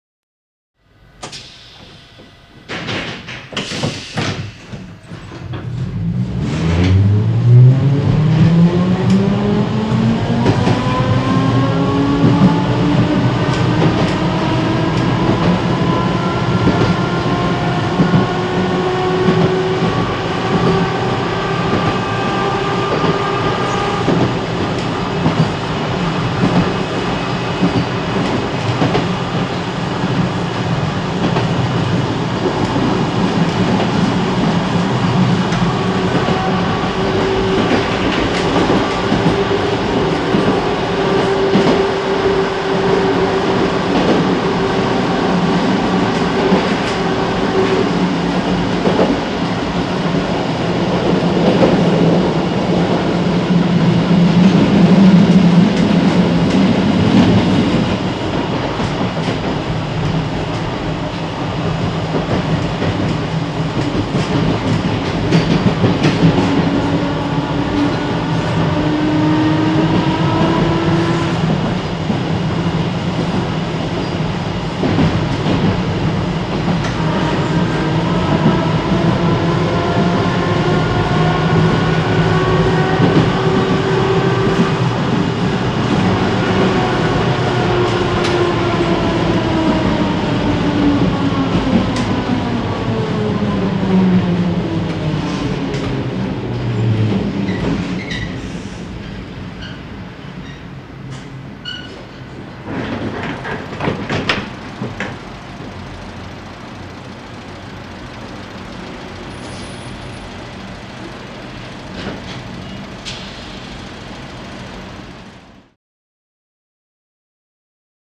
Start / Stop ( Interior ); Interior French Tram Starts, Departs, And Stops.